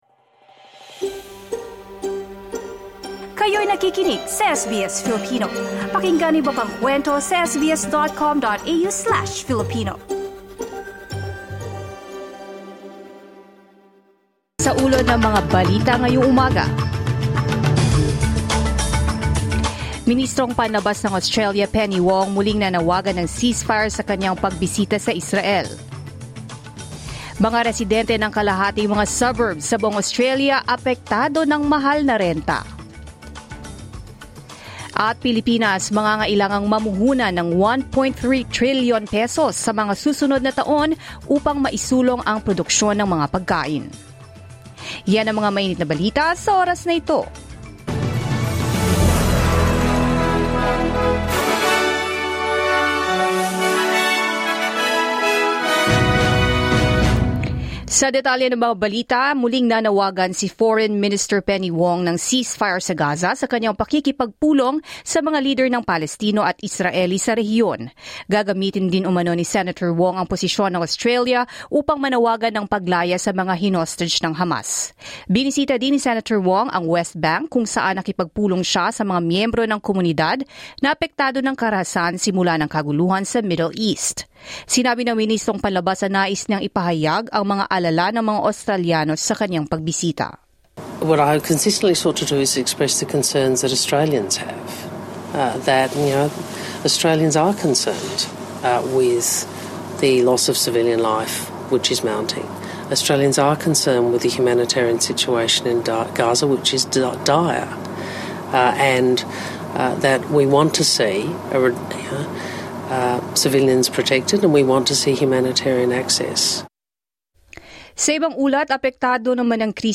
SBS News